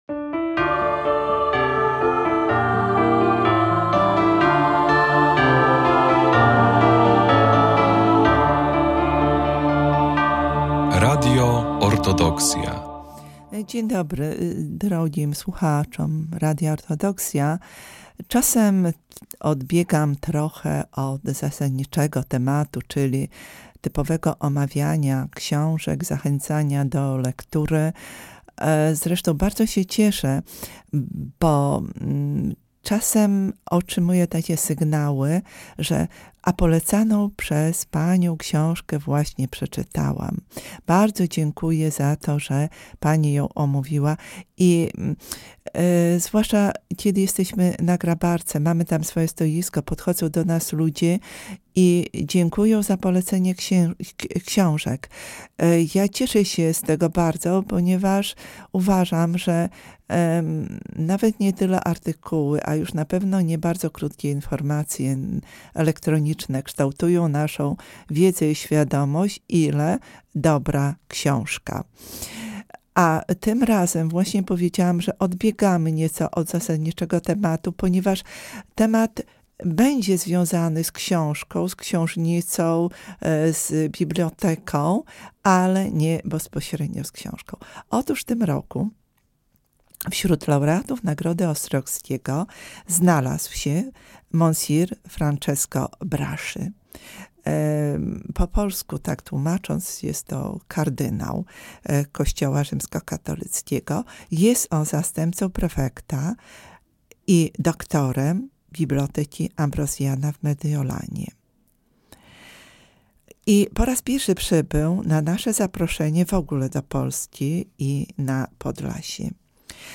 Prowadząca prezentuje i omawia wybrane lektury warte uwagi, często związane z kulturą, historią i duchowością, dzieląc się refleksjami i czytelniczymi rekomendacjami.